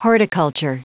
Prononciation du mot horticulture en anglais (fichier audio)
horticulture.wav